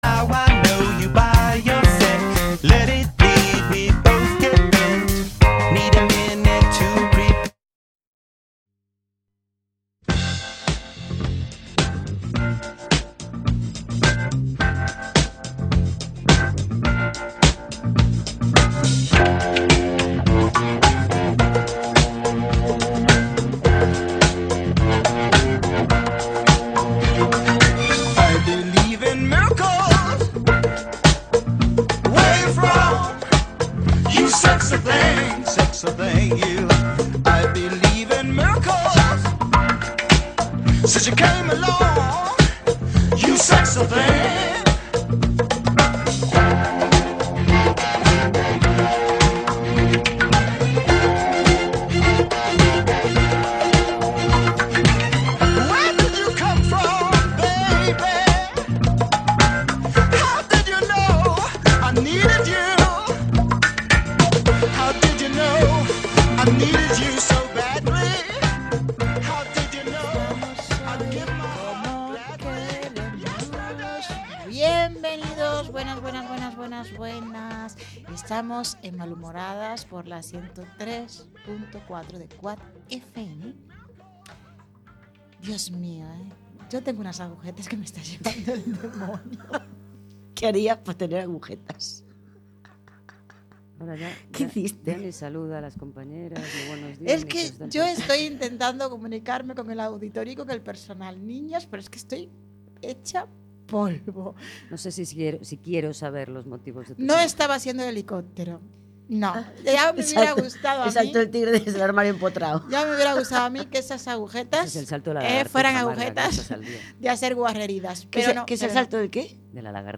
Somos un par de malhumorhadas que, en cada programa, creceremos en número y en espíritu, acompañándonos de personas que, como nosotras, se pre-ocupan y se ocupan de las cosas importantes, dando apoyo a proyectos y movimientos sociales con diferentes causas y objetivos. Todo esto con mucho humor (bueno y malo) y con mucha música y diversión, todos los miércoles a las 20:00 en Cuac FM (redifusión: sábados 10:00) y los jueves a las 14:00 en OMC Radio.